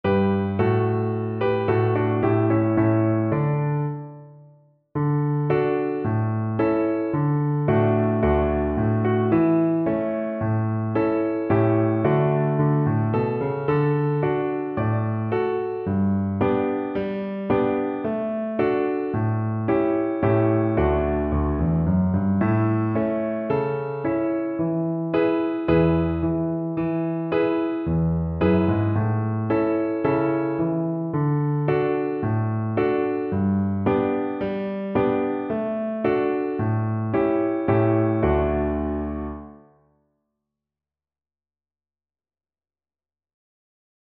4/4 (View more 4/4 Music)
Moderately fast =c.110
Classical (View more Classical Cello Music)